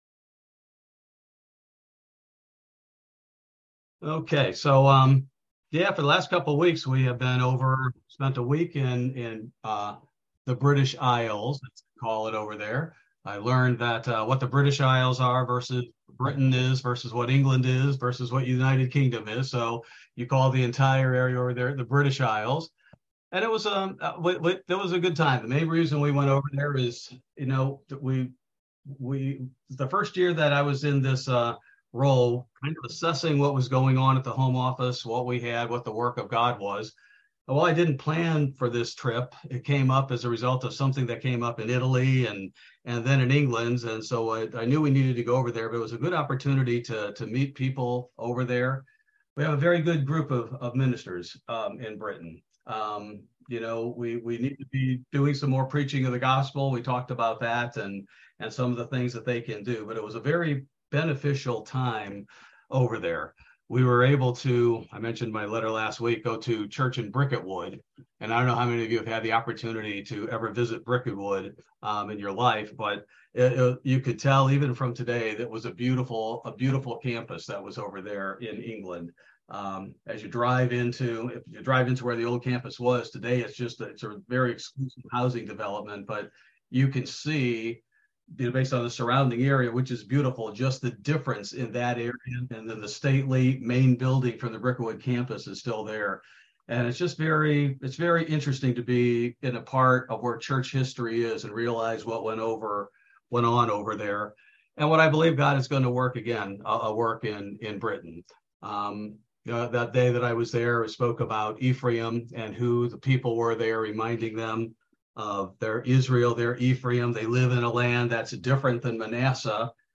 This verse by verse Bible Study primarily covers Isaiah 44 -- Cyrus Named by Name and also has a brief Update on England and Italy Church Visits